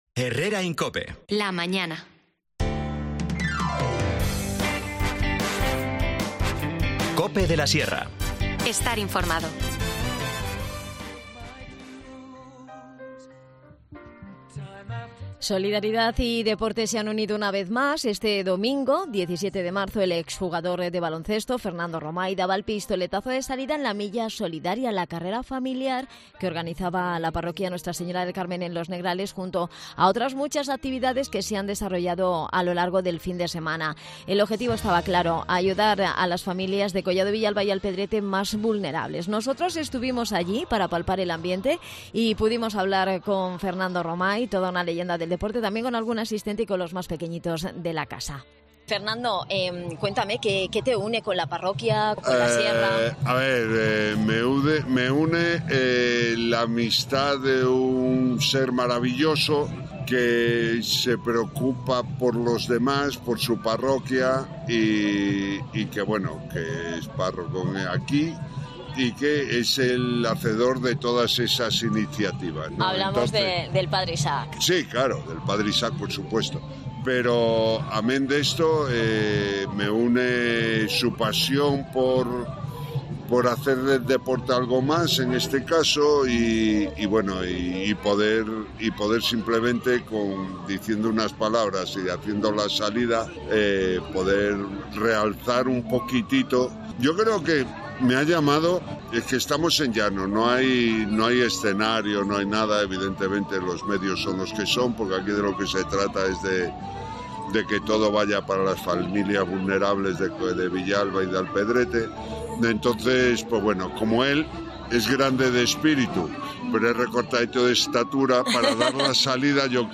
Aprovechamos este evento para palpar el ambiente y hablar con Fernando Romay.